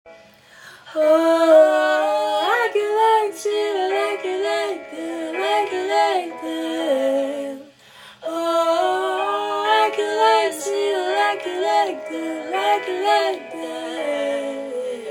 Chanteuse
13 - 22 ans - Contralto